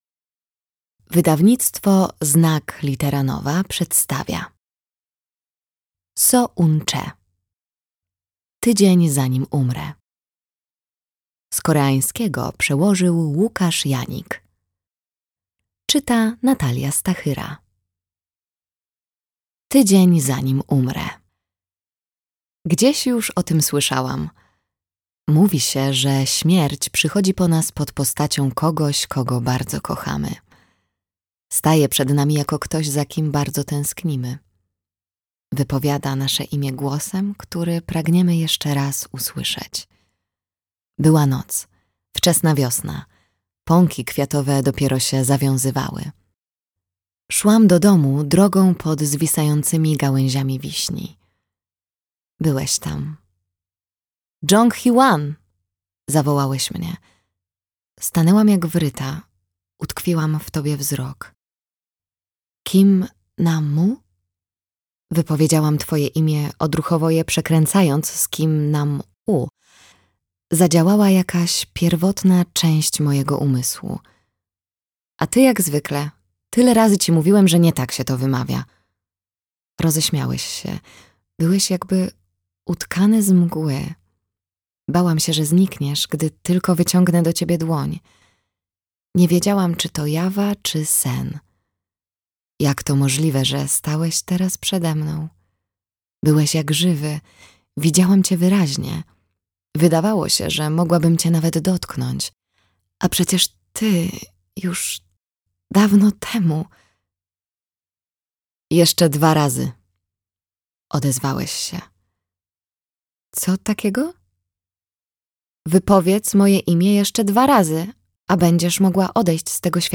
Tydzień, zanim umrę - Seo Eun-chae - audiobook + książka